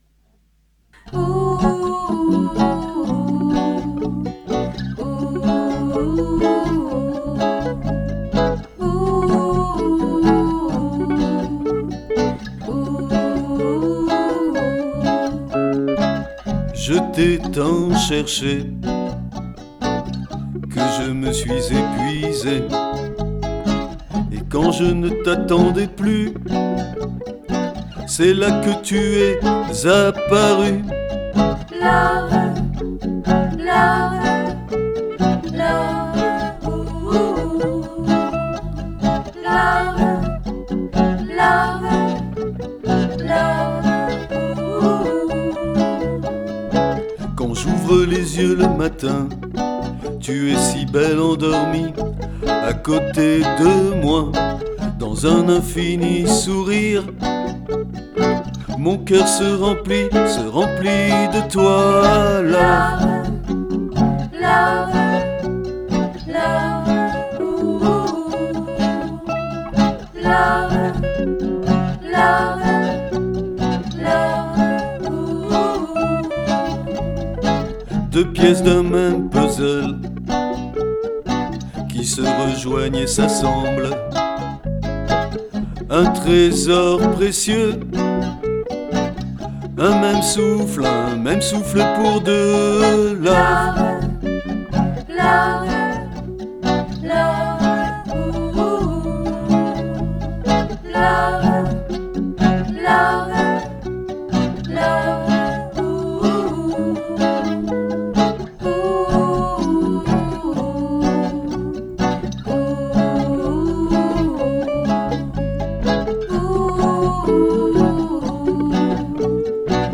guitar, bass, cajon, vocals
violin
flute